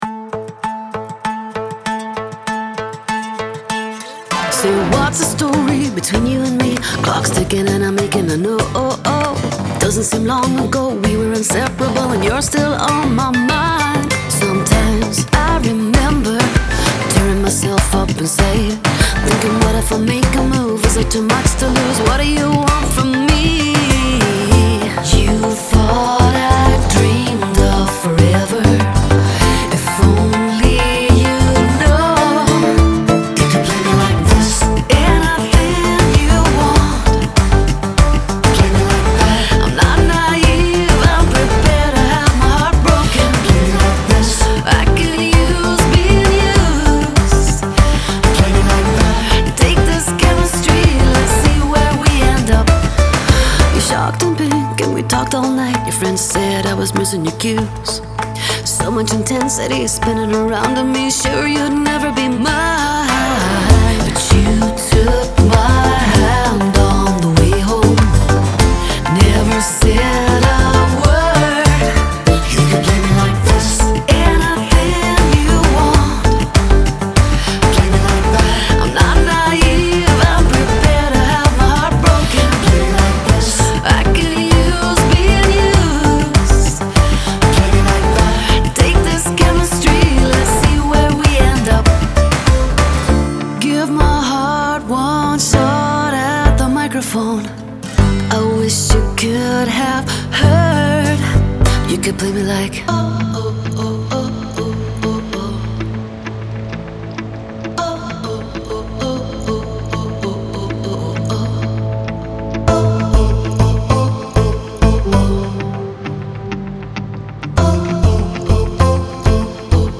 I work in the pop and dance genres from my base in Glasgow.